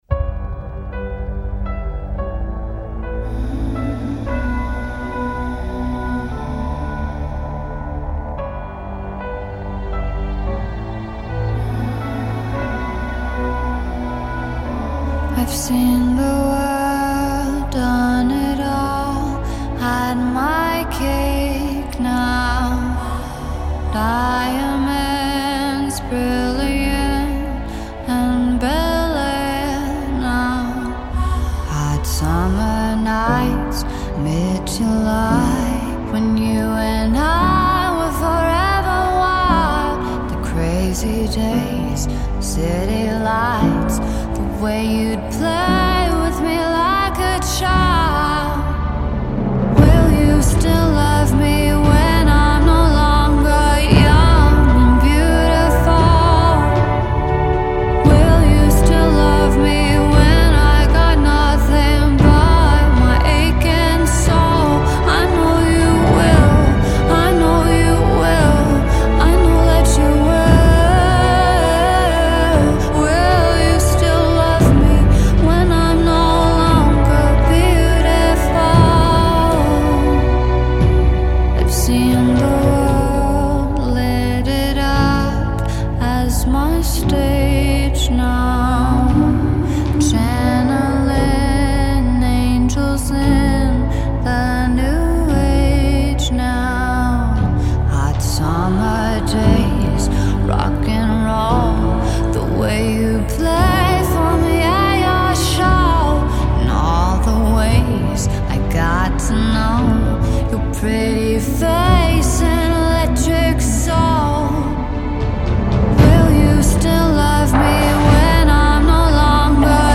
haunting song